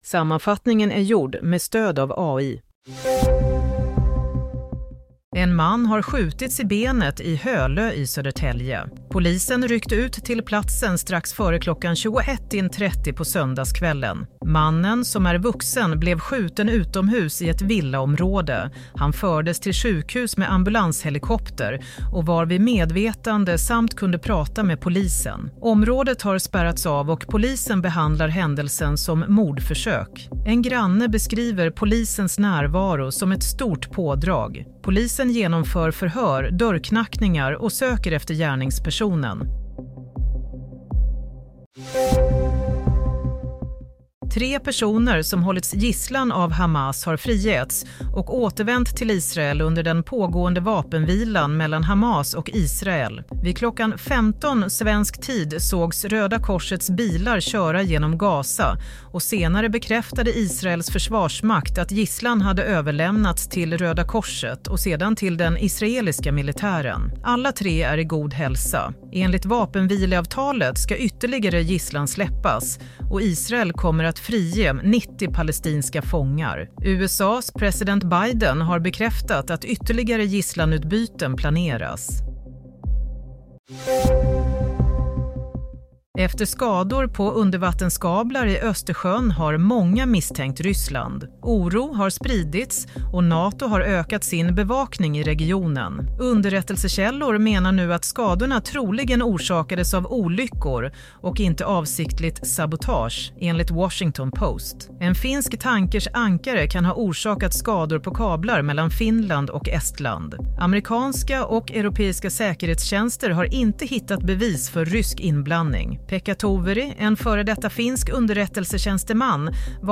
Nyhetssammanfattning – 19 januari 22:00